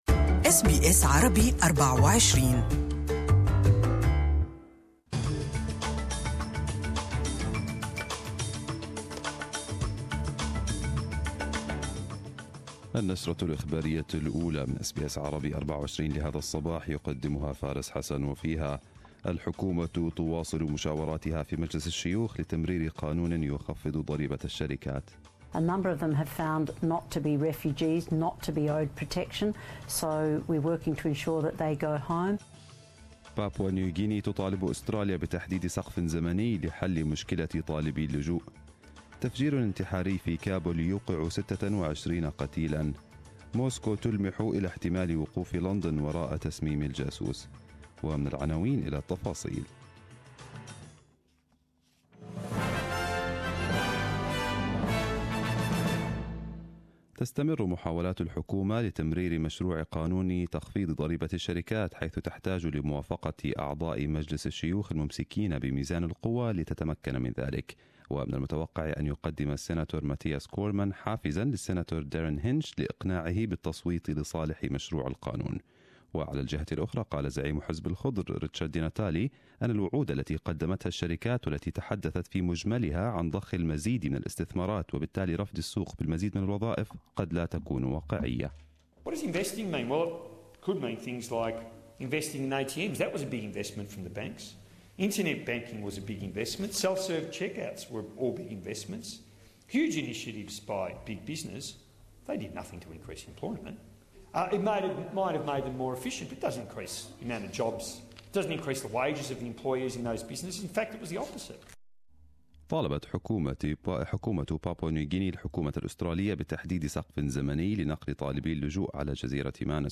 Arabic News Bulletin 22/03/2018